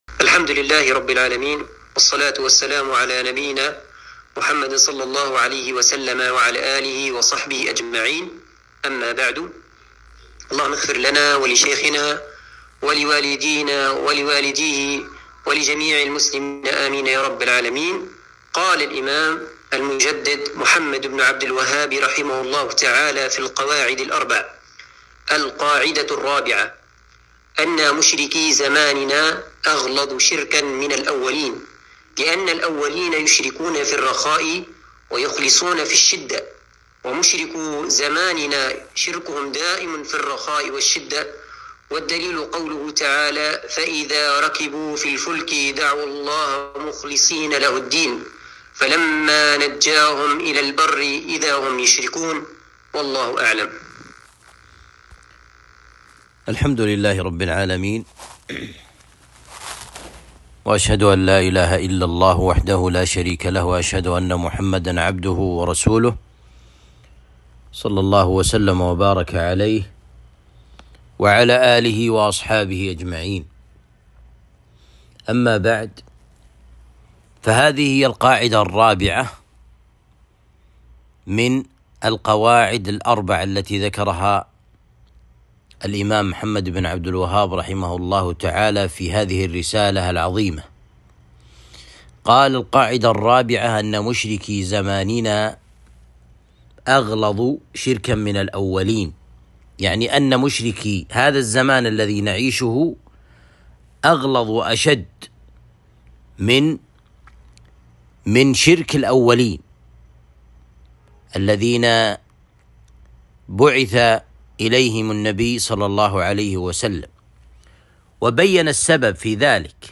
القواعد الأربع الدرس الرابع